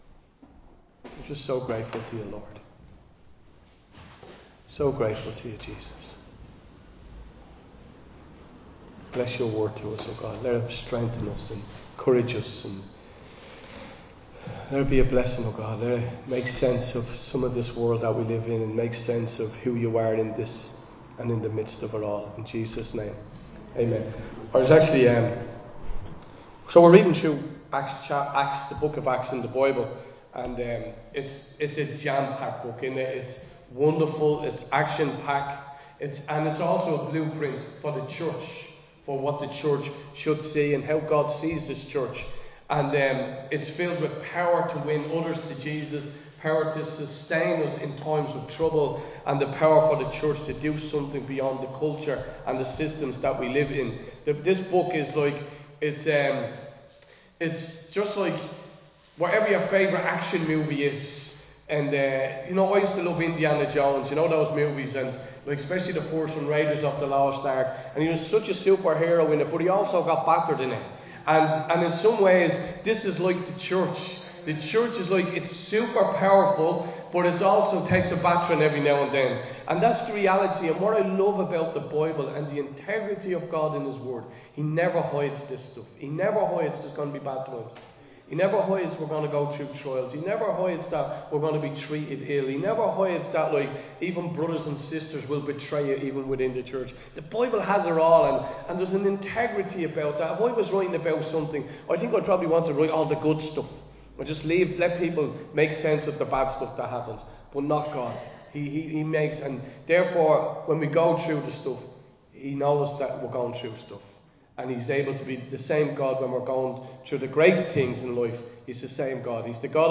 Recorded live in Liberty Church on 23 February 2025